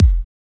Kick Quasi 2.wav